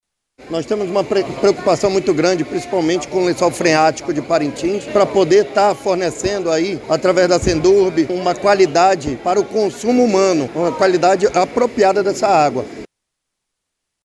O diretor-presidente do Ipaam, Gustavo Picanço, ressalta que todos os critérios estabelecidos pela legislação ambiental foram integralmente cumpridos.